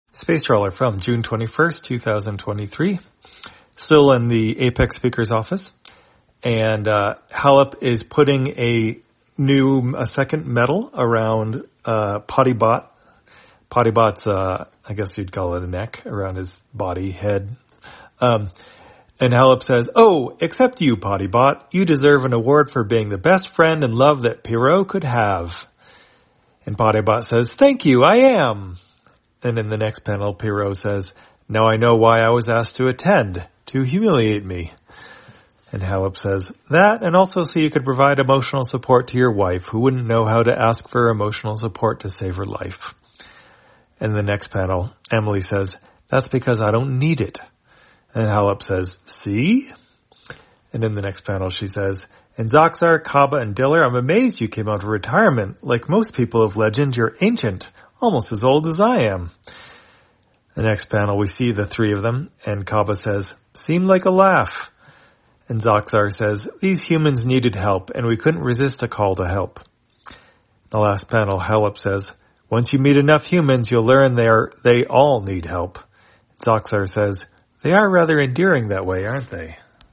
Spacetrawler, audio version For the blind or visually impaired, June 21, 2023.